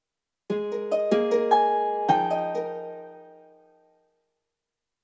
Identité sonore